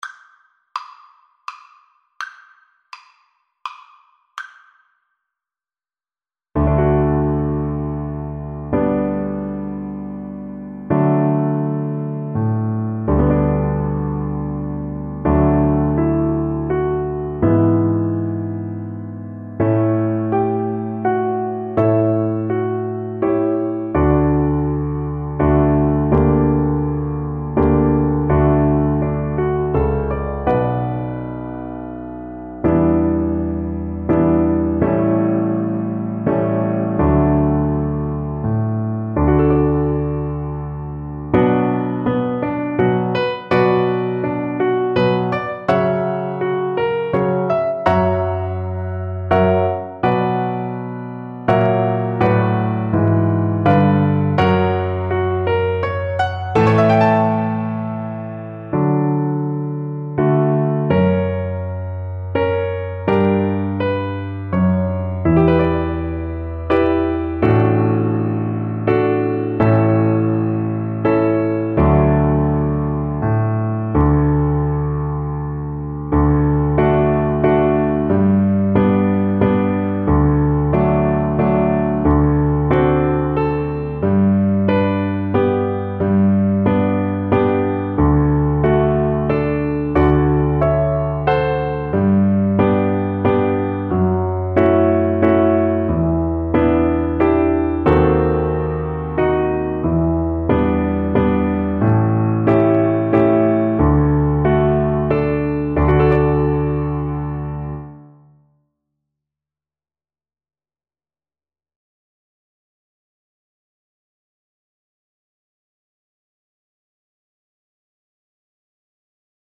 Violin version
3/4 (View more 3/4 Music)
Slow one in a bar .=c.40
Violin  (View more Easy Violin Music)
Traditional (View more Traditional Violin Music)
world (View more world Violin Music)